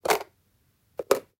Tiếng Mở Nắp Hộp Nhựa đã đóng
Thể loại: Tiếng đồ vật
Description: Tiếng mở nắp hộp nhựa đã đóng là âm thanh xuất hiện khi có hoạt động của con người mở nắp hộp đã được đóng chặt, để sử dụng đồ trong hộp hoặc cất vật gì đó vào hộp.
Tieng-mo-nap-hop-nhua-da-dong-www_tiengdong_com.mp3